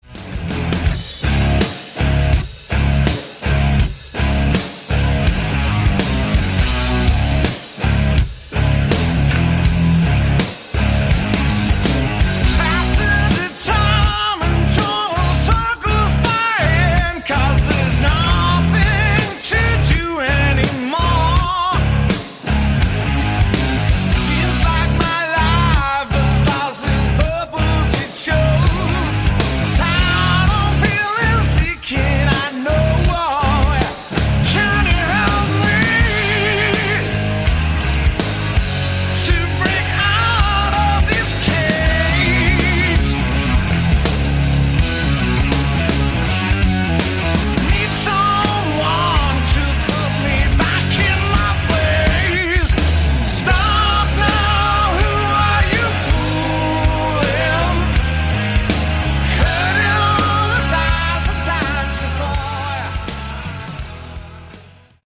Category: Hard Rock
lead vocals
guitar, vocals
backing vocals